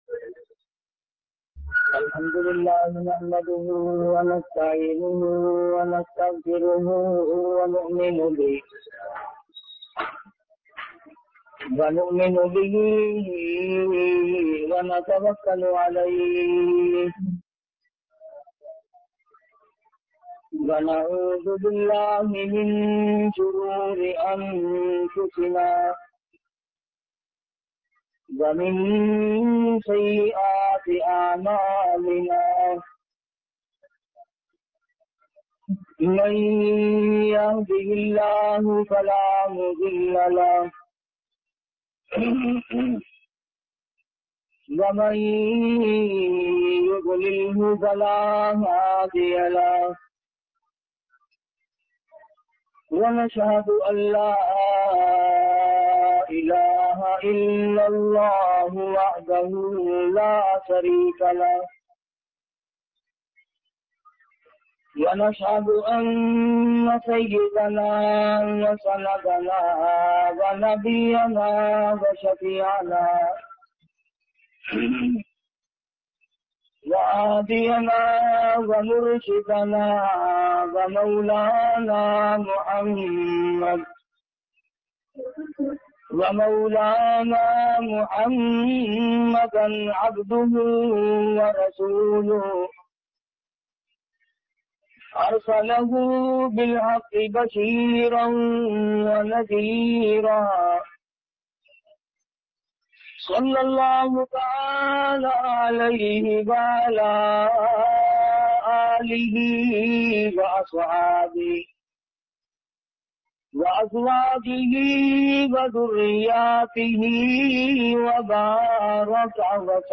Arsh e azam bayan MP3